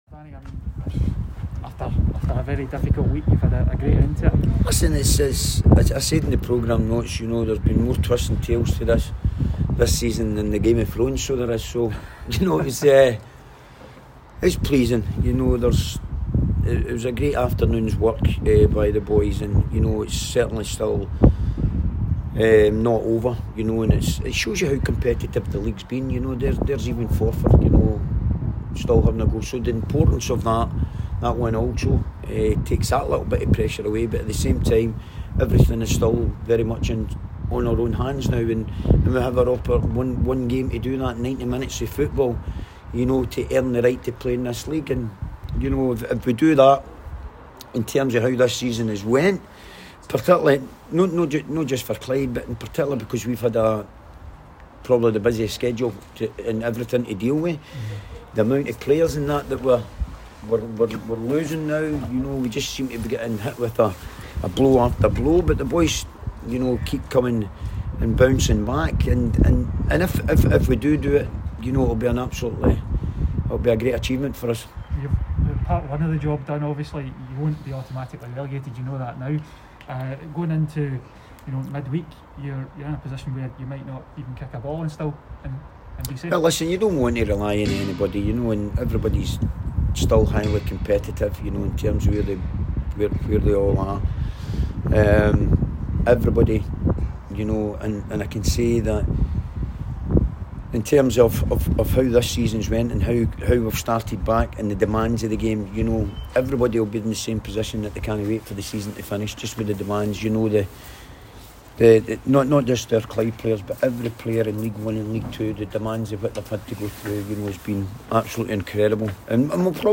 press conference after the League 1 match.